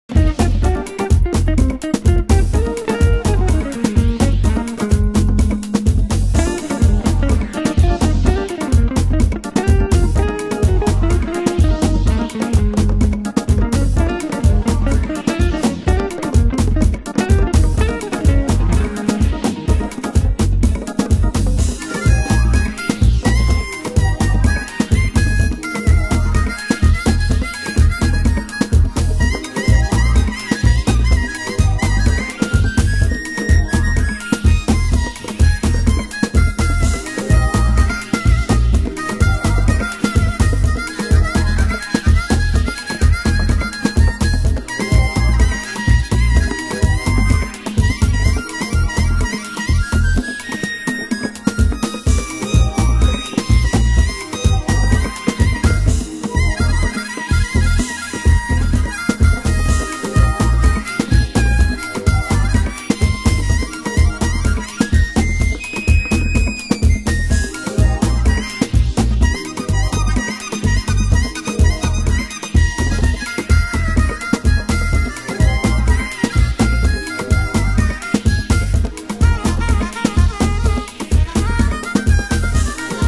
HOUSE MUSIC
Rhythm Mix
盤質：少しチリパチノイズ有　　ジャケ：良好